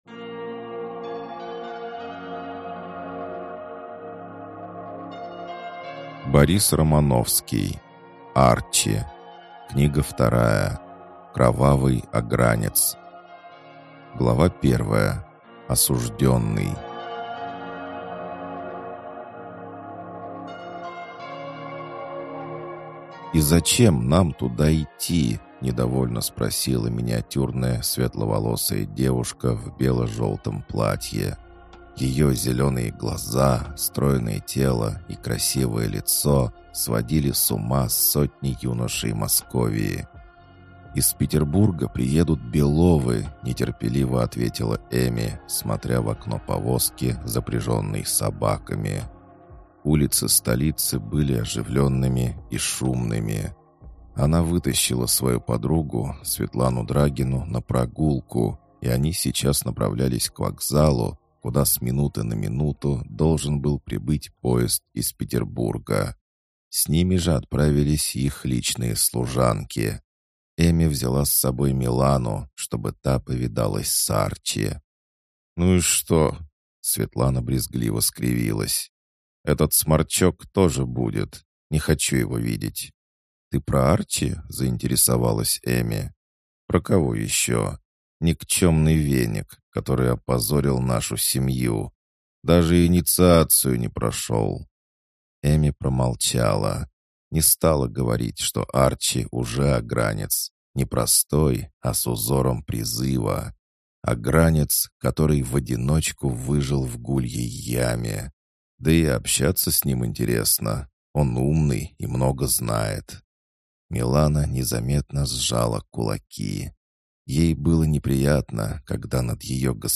Аудиокнига Кровавый Огранец | Библиотека аудиокниг